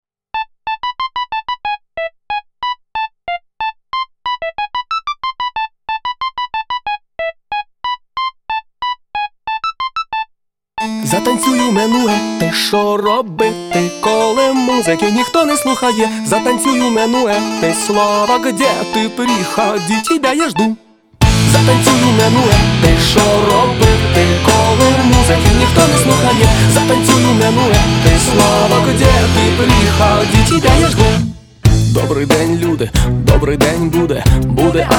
Жанр: Рок / Украинские